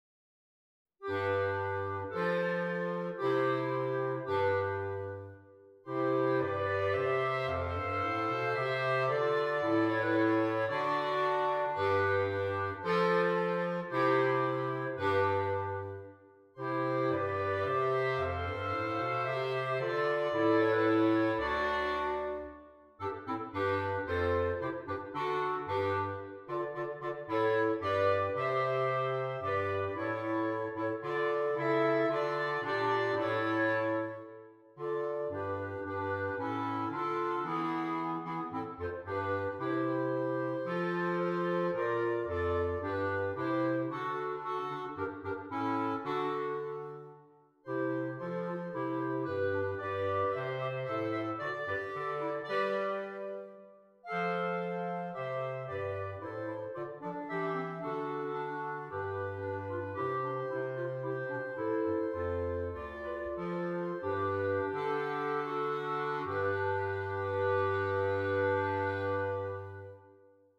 3 Clarinets, Bass Clarinet